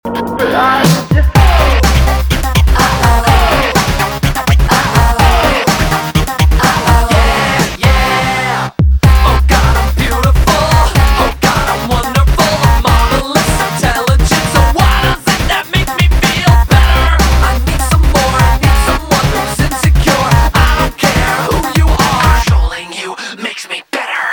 рок
гитара , барабаны , жесткие